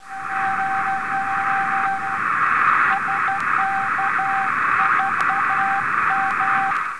Zwischen den kräftigen Pings und Bursts waren die Baken oft über Minuten leise zu hören. Oft war ein kräftiger Ping von einem unmittelbar übergehenden Burst von bis zu 45 Sekunden gefolgt.
WAV-Datei, um auch akustisch einen Eindruck des Bakensignals via MS von SK4MPI zu erhalten.